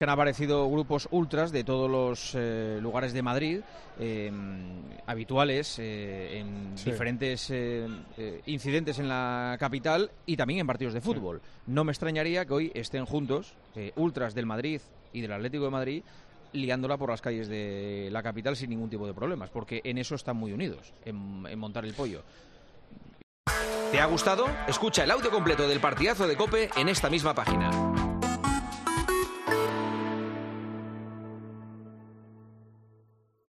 AUDIO: Una nueva jornada de tensión en torno a la sede del PSOE en Madrid llegó hasta El Partidazo de COPE y su director hizo este comentario sobre los radicales